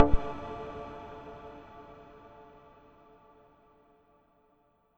pause-back-click.wav